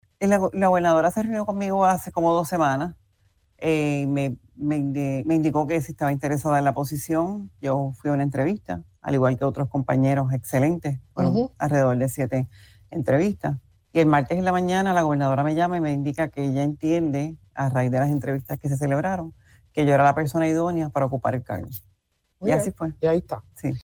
La designada secretaria del Departamento de Justicia, Lourdes Lynnette Gómez Torres, aseguró en entrevista para Radio Isla que actuará conforme a la ley y el derecho en situaciones que traten el tema del aborto.